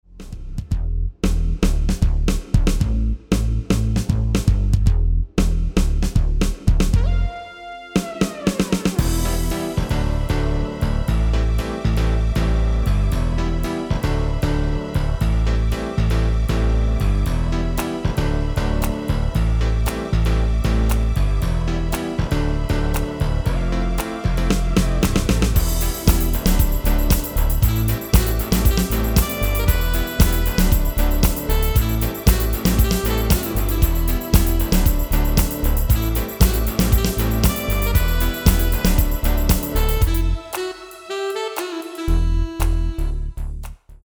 Demo/Koop midifile
Genre: Actuele hitlijsten
Toonsoort: Em
- GM = General Midi level 1
- Géén vocal harmony tracks
Demo = Demo midifile